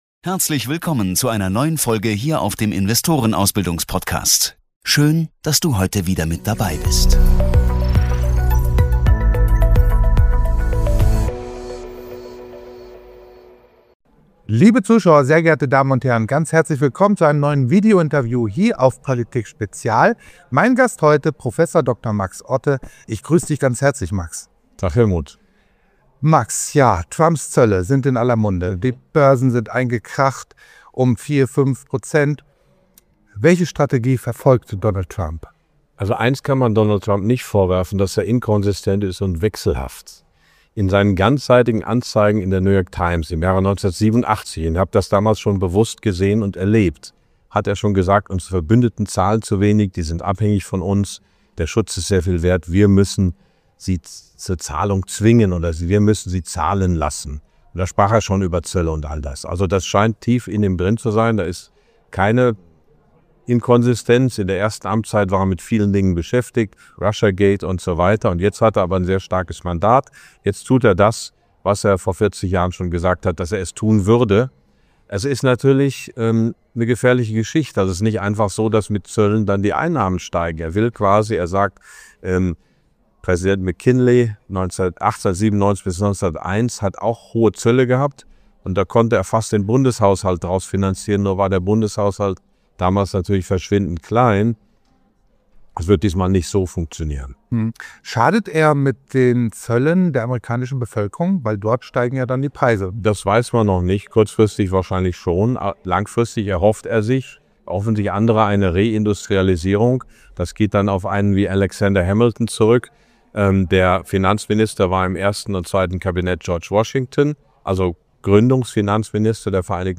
Beschreibung vor 1 Jahr In diesem Interview analysiert Prof. Dr. Max Otte die wirtschaftlichen Folgen von Trumps Zollpolitik und die aktuelle Lage der deutschen Wirtschaft. Er bewertet die Koalitionsverhandlungen zwischen CDU und SPD und gibt eine Einschätzung zur Zukunft deutscher Unternehmen. Zudem werden verschiedene Anlageoptionen wie Immobilien, Gold und Investments mit kleinem Budget thematisiert.